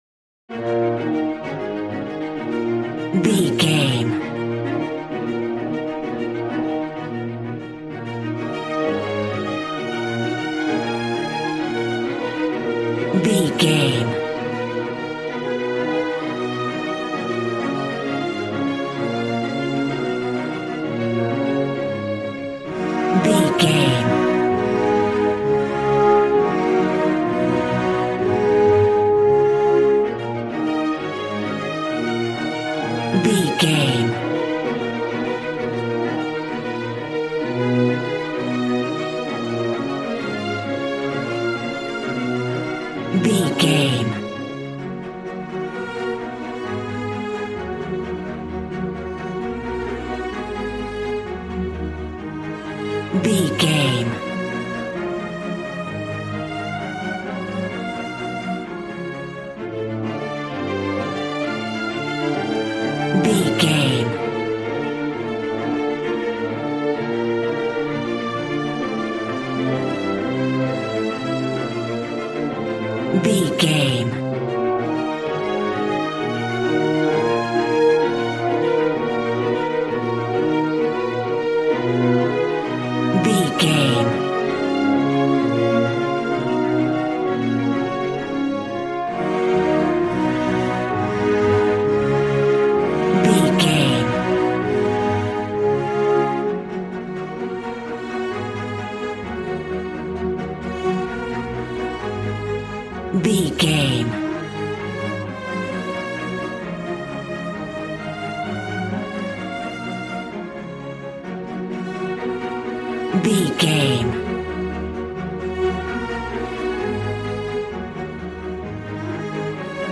Regal and romantic, a classy piece of classical music.
Aeolian/Minor
E♭
regal
cello
violin
strings